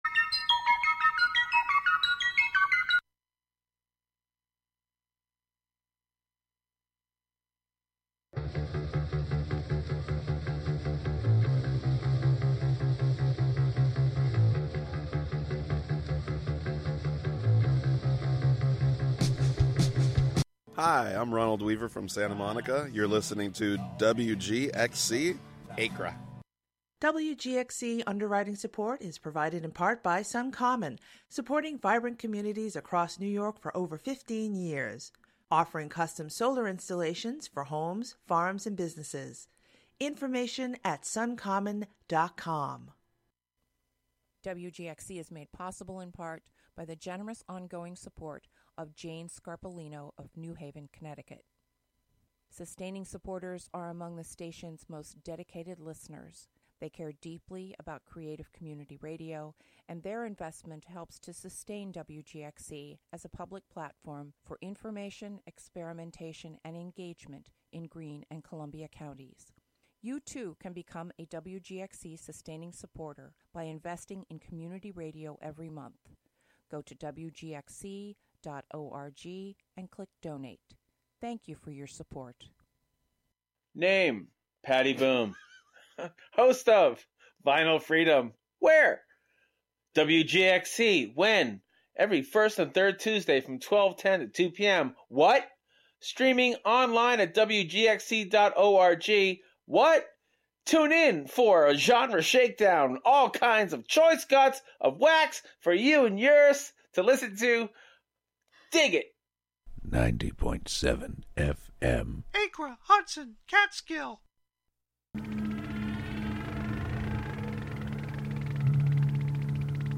Live from the Catskill Makers space on W. Bridge St. in Catskill, a monthly show about science, technology, fixing, making, hacking, and breaking with the amorphous collection of brains comprising the "Skill Syndicate."